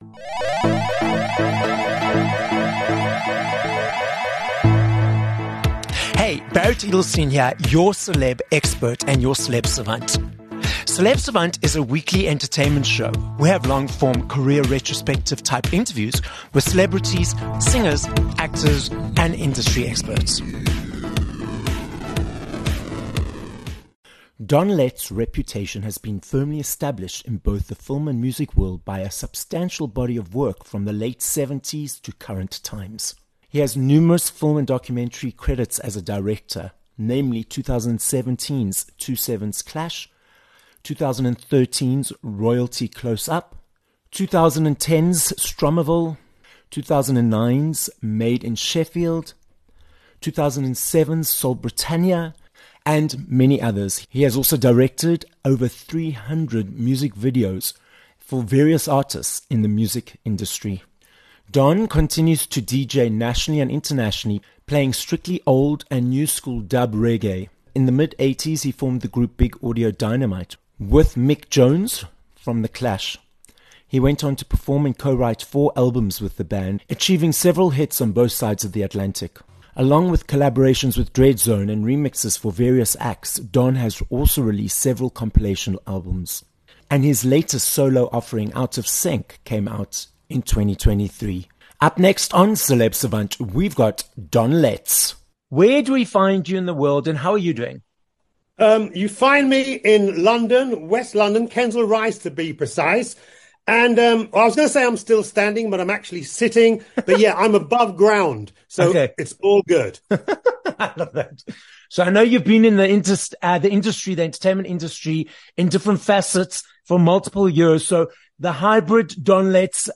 19 Dec Interview with Don Letts